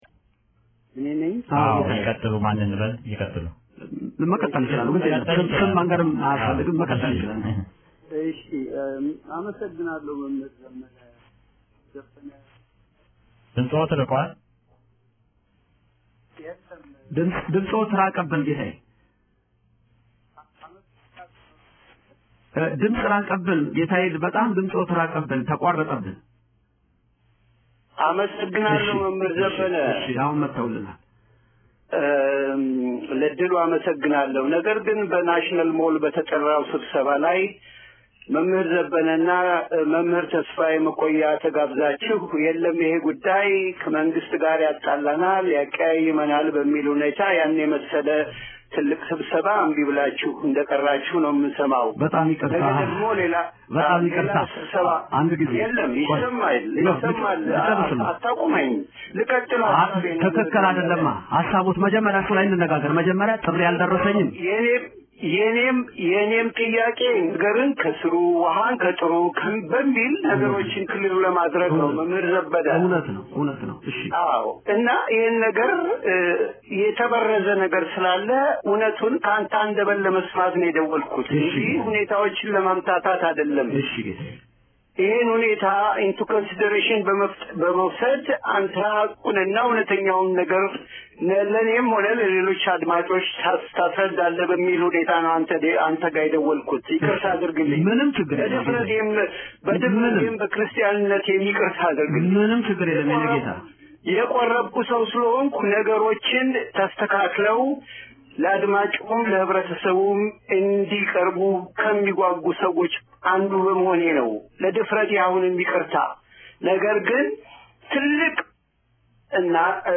በቀጥታ በሚተላለፈው 1120 ኤ.ኤም ራዲዮ “ደምጸ ተዋህዶ” መርሃ ግብር ላይ አንድ ተቆርቋሪ ምዕመን ስልክ በመደወል
ይህም በሬዲዮ የተላለፈ የክህደት ቃል በርካታ የኢ/ኦ/ተ/ቤ/ክ ምዕመናንን በማነጋገርና በማወያየት ላይ ነው።